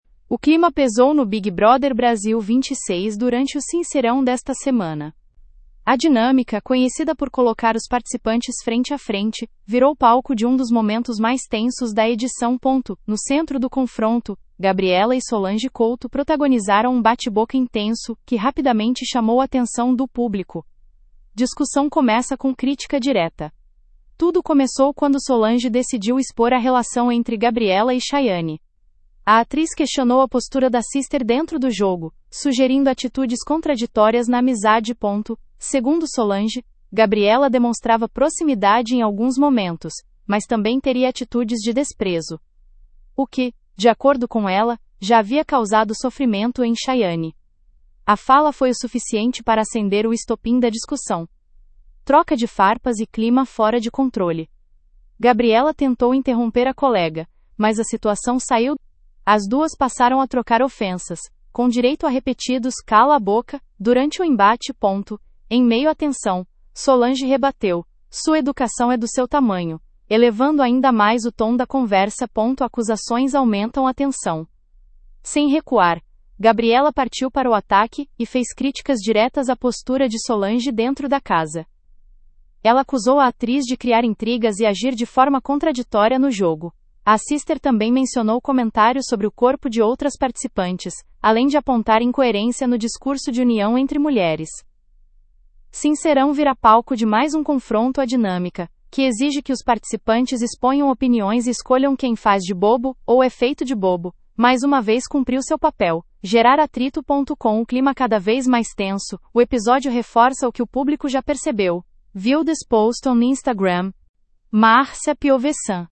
As duas passaram a trocar ofensas, com direito a repetidos “cala a boca” durante o embate.